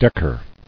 [deck·er]